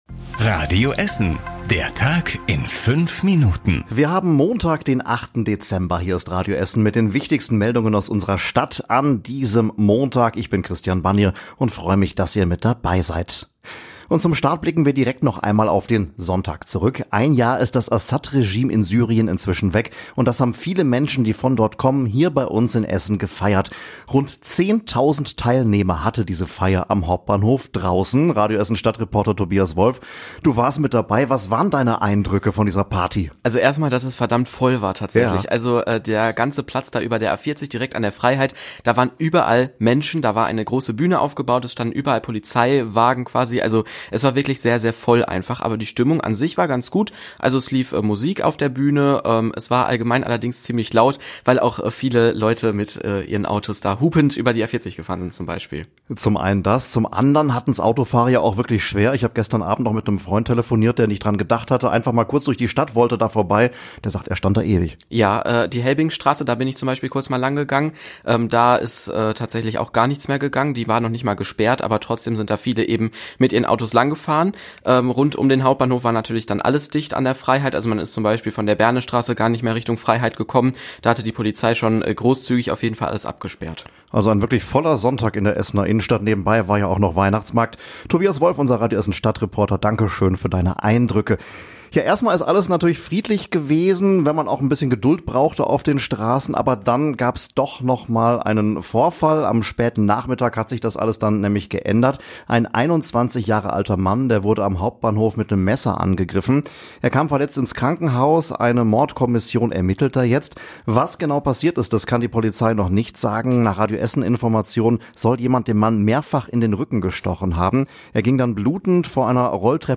Die wichtigsten Nachrichten des Tages in der Zusammenfassung
Unser Stadtreporter berichtet heute im Nachrichten-Podcast!